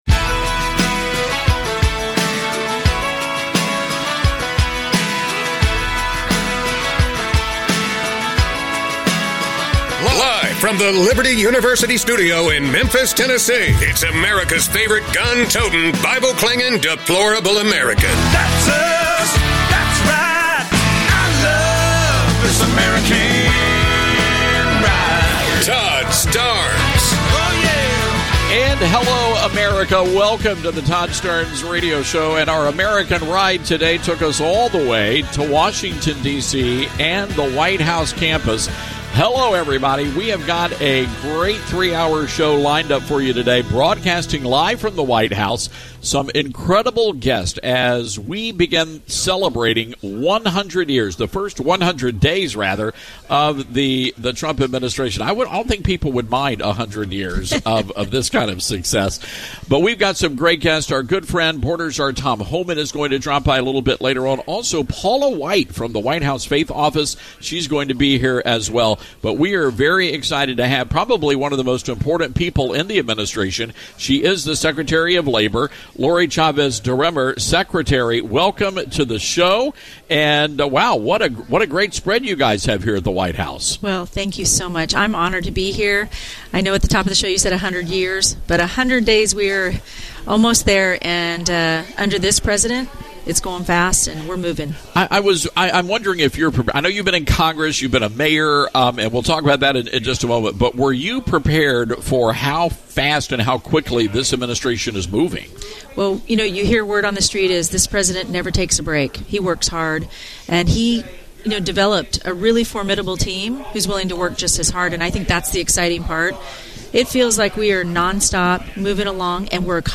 TODD STARNES IS LIVE FROM THE WHITE HOUSE!
Todd was invited to broadcast his entire show today from the White House.